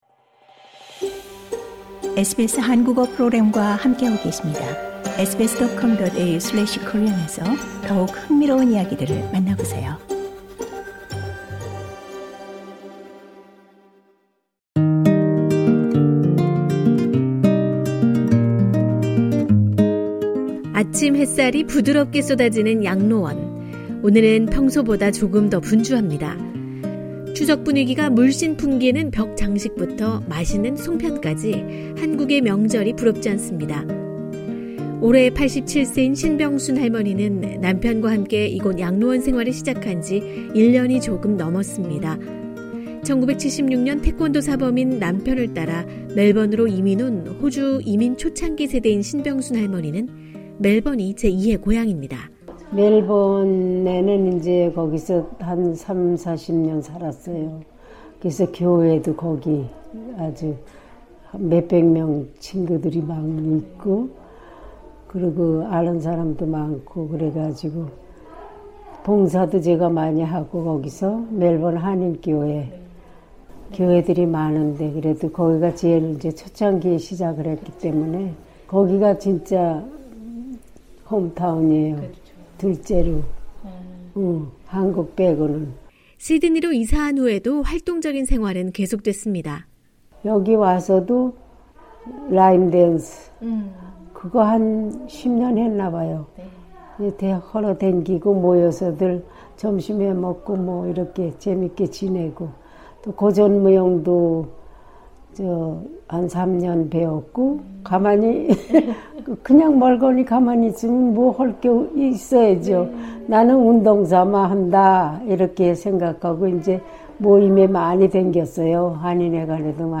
멀리 떨어져 있어도 마음만은 늘 가족 곁에 있는 추석 명절을 맞아 시드니 북쪽 애스퀴스(Asquith) 지역에 위치한 한인 전용 양로원을 찾아가 봅니다.